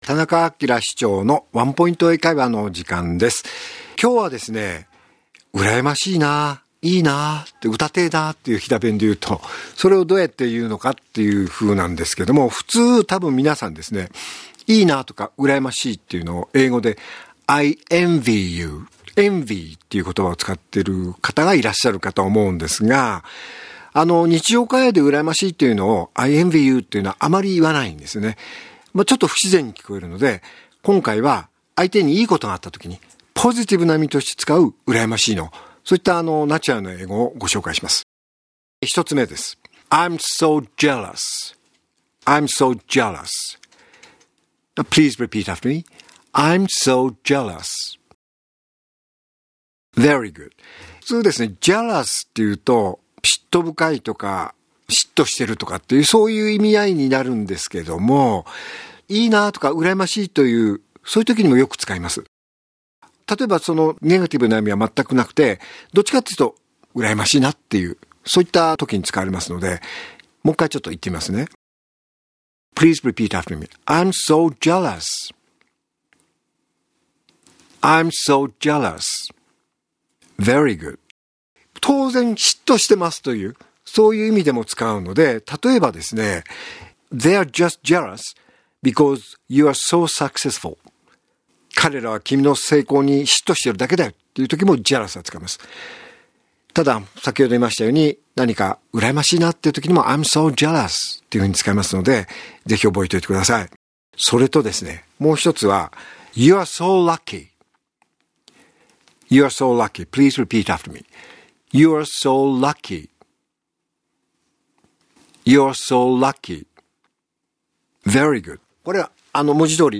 R5.11 AKILA市長のワンポイント英会話